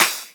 osu-logo-downbeat.wav